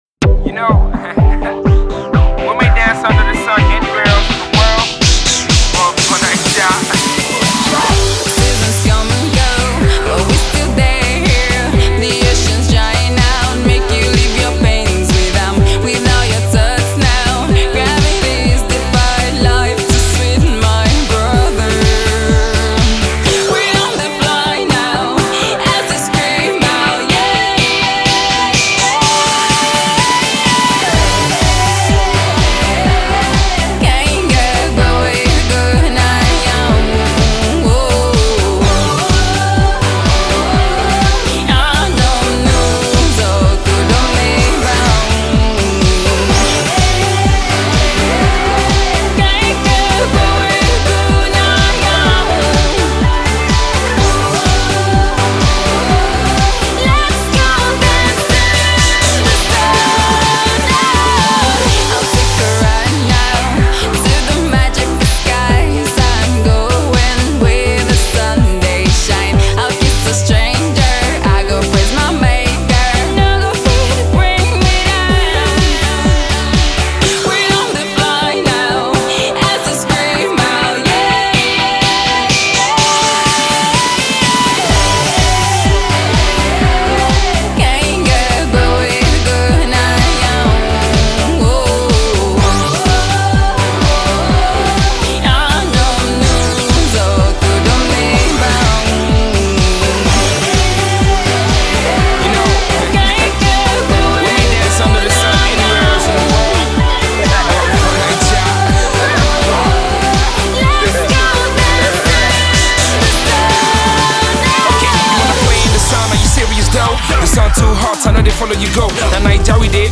The uptempo pop/rock song also features the lyrical genius
clearly Pop/rock song that still oozes Nigeria.